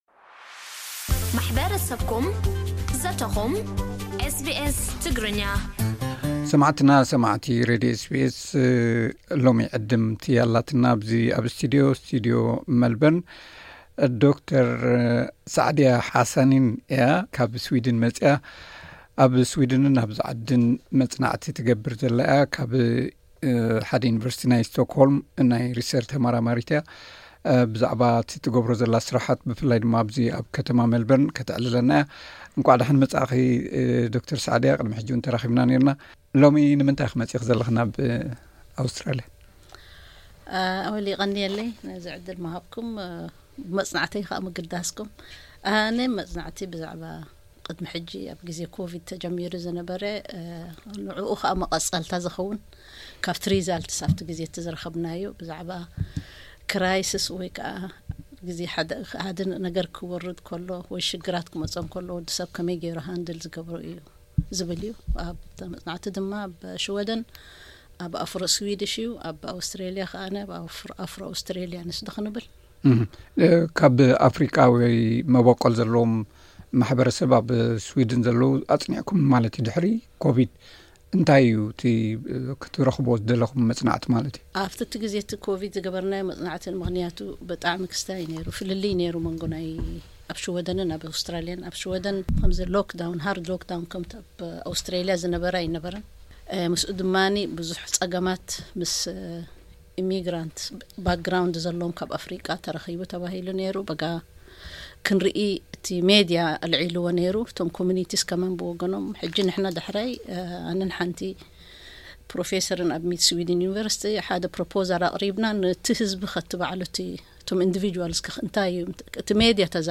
ብዛዕባ ትገብሮም መጽናዕትታትን ብሓፈሻ ብዛዕባ ስርሓን ኣዕሊላትና ኣላ።